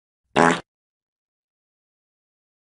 1. صدای گوز کوتاه: دانلود (100 کیلوبایت)
Fart-Sound.mp3